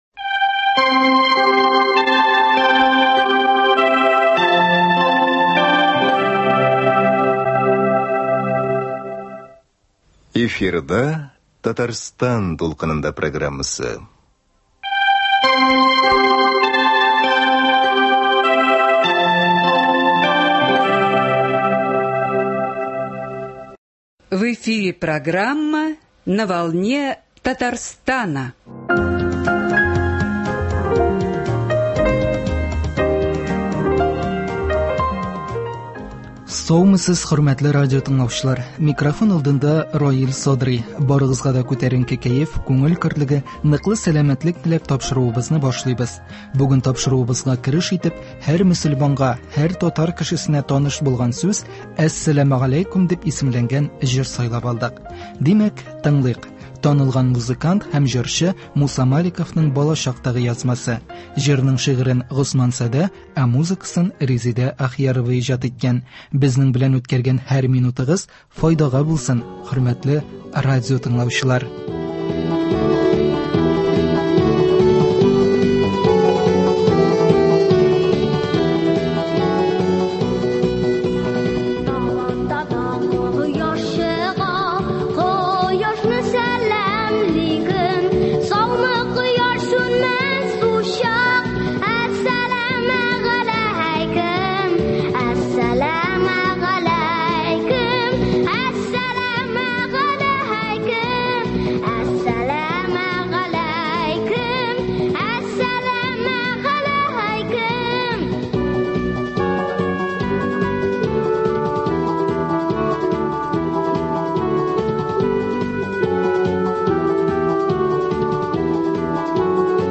Сез дә, хөрмәтле радиотыңлаучылар, аның тырышлыгына сокланып, әлегә сөйләмендә булган хаталарны гаепкә алмассыз дип өметләнәбез, чөнки телгә өйрәнгән вакытта тирә-юньдәге кешеләрнең ярдәме һәм үсендереп торуы бик мөһим нәрсә.